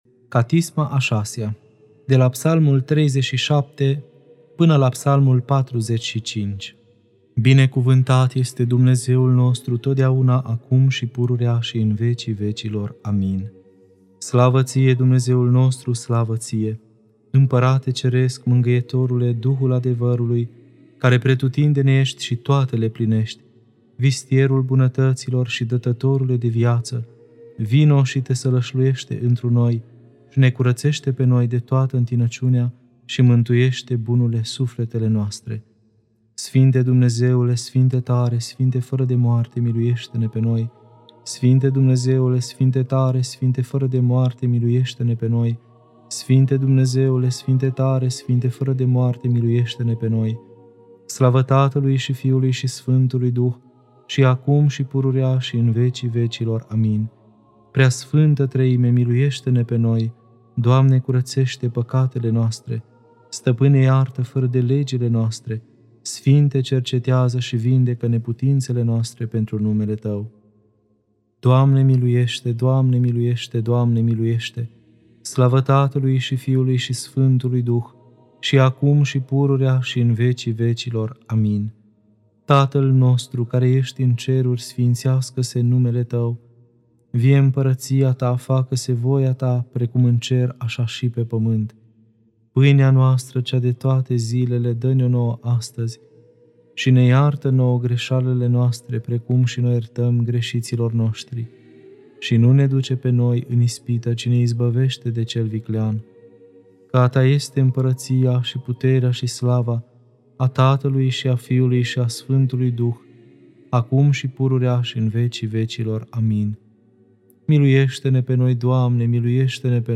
Catisma a VI-a (Psalmii 37-45) Lectura